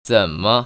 [zěn‧me]